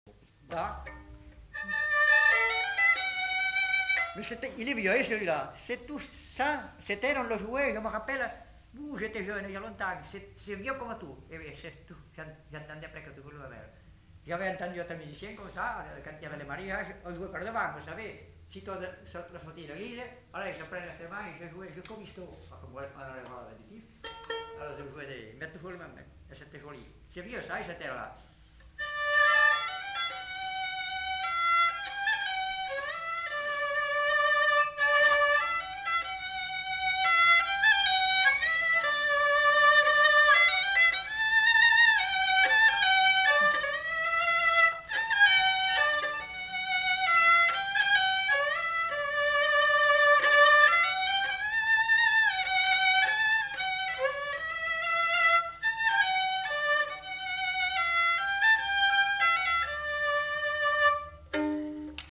Lieu : Casteljaloux
Genre : morceau instrumental
Instrument de musique : violon
Notes consultables : Donne des précisions sur ce morceau en début de séquence.